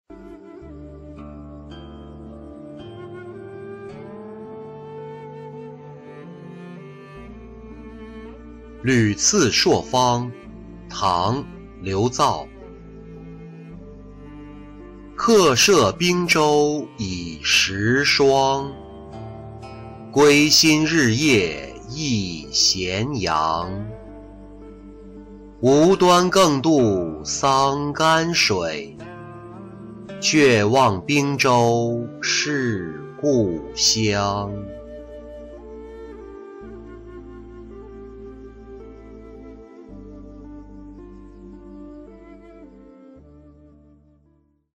旅次朔方-音频朗读